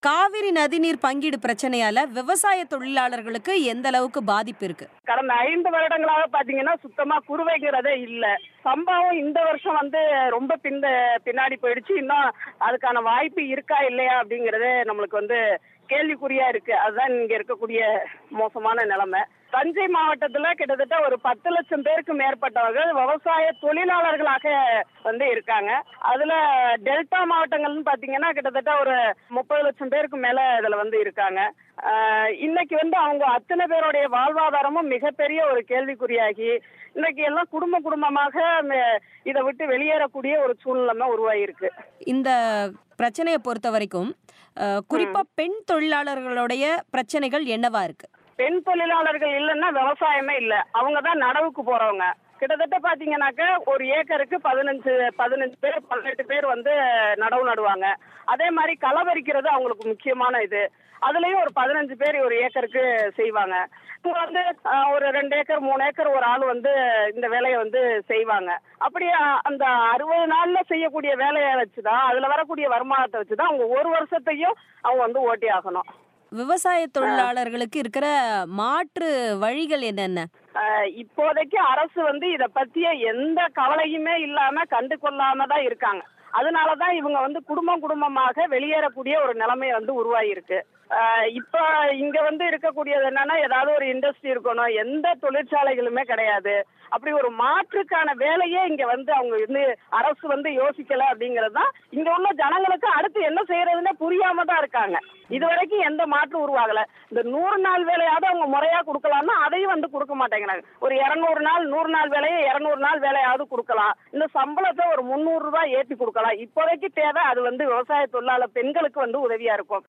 காவிரி நதி நீர் பங்கீடு பிரச்சனையால் தமிழக விவசாயத் தொழிலார்களின் சந்திக்கும் பாதிப்புகள் பற்றிய பேட்டி